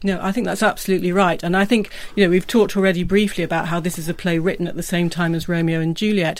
So much so that, as the following examples demonstrate, it also happens after vowels, which is more surprising and not very often described in the literature.